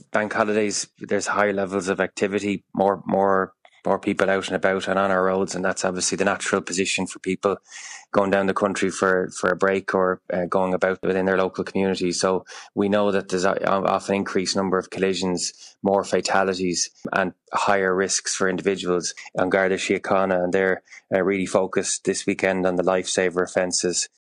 Minster of State with Special Responsibilities for Road Safety Jack Chambers, is urging road users to take care: